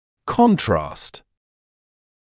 [‘kɔntrɑːst][‘контраːст]контраст; контрастное вещество